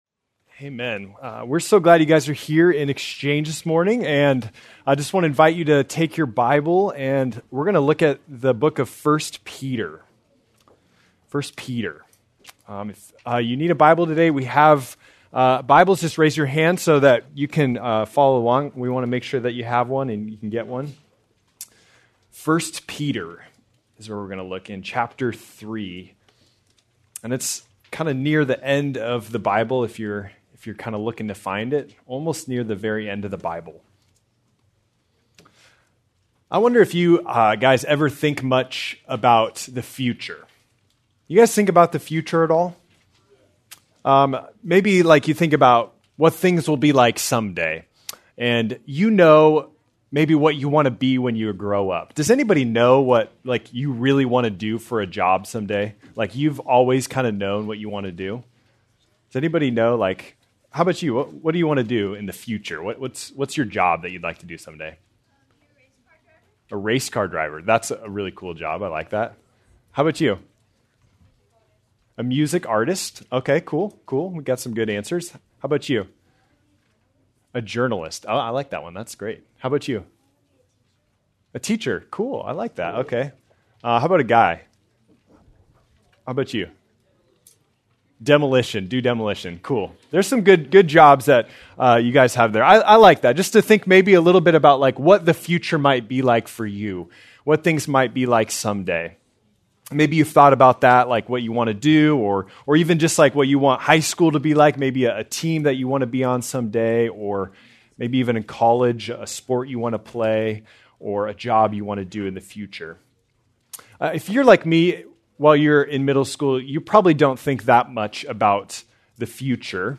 January 18, 2026 - Sermon | Xchange | Grace Community Church